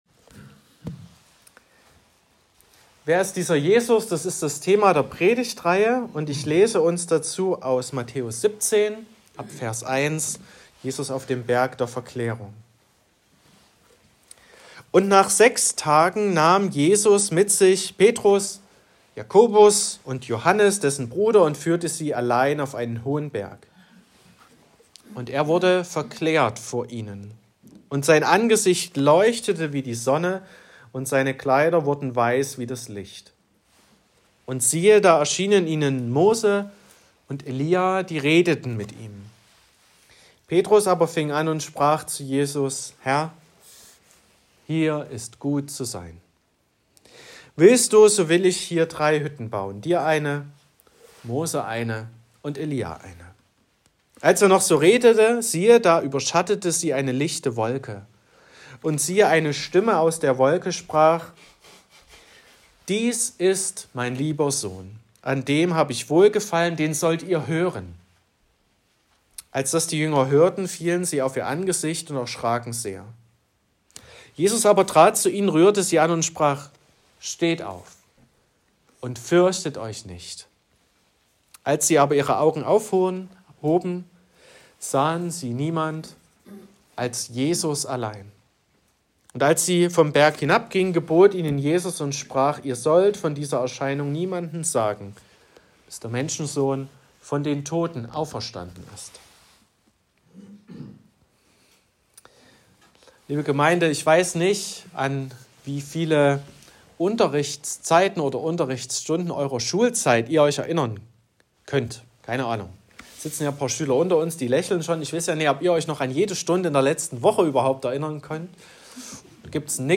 19.01.2025 – Gottesdienst
Predigt (Audio): 2025-01-19_Jesus_allein__Predigtreihe_2025__Thema_4_.m4a (10,3 MB)